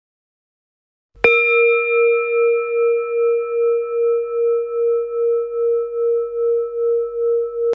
Âm thanh tịnh tâm an sound effects free download
Âm thanh tịnh tâm an thần, cải thiện chứng mất ngủ.